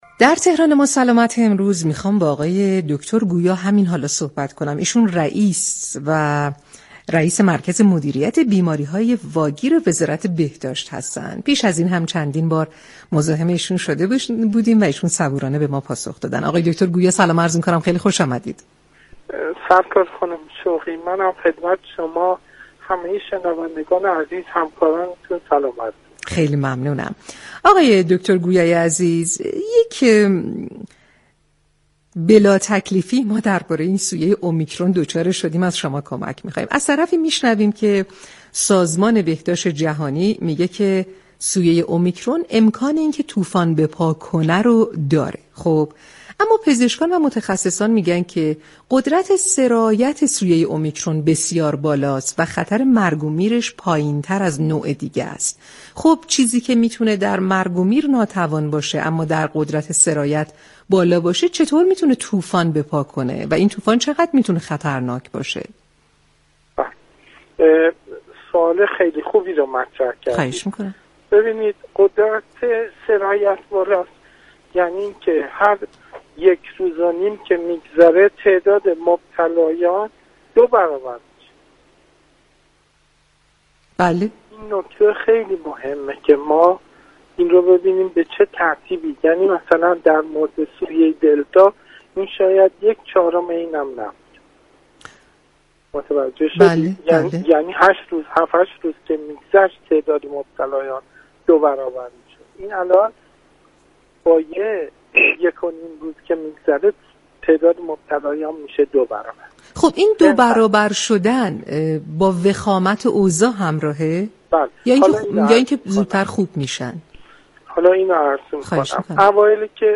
به گزارش پایگاه اطلاع رسانی رادیو تهران، دكتر محمدمهدی گویا رئیس مركز مدیریت بیماریهای واگیر وزارت بهداشت در گفتگو با برنامه تهران ما سلامت رادیو تهران با اشاره به نظرات مختلف پزشكان و متخصصان و سازمان بهداشت جهانی درباره سویه اُمیكرون گفت: قدرت سرایت بالای اُمیكرون یعنی اینكه پس از گذشت هر یك روز و نیم تعداد مبتلایان دوبرابر میشود.